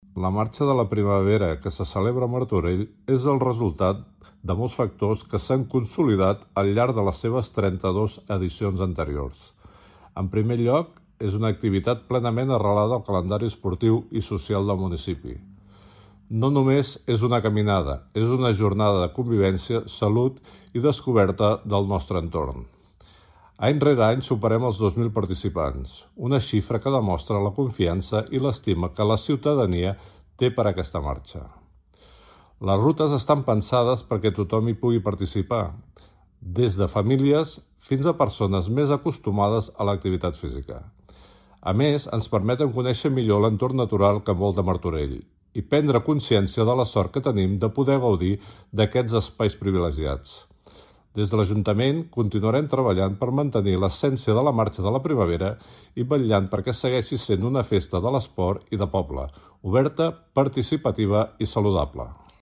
Gerard Mimó, regidor d'Esports de l'Ajuntament de Martorell